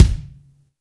INSKICK15 -R.wav